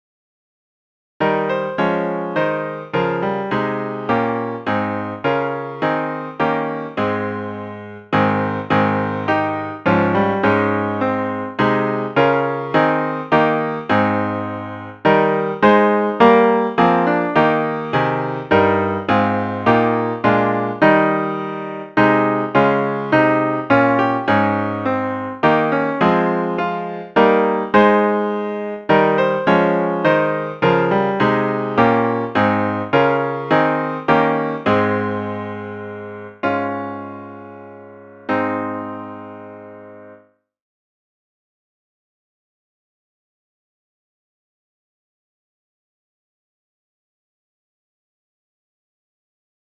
Plus, we're offering accompaniments (most with introductions) that you can play on a cell phone or other device to enjoy by yourself or at your family table.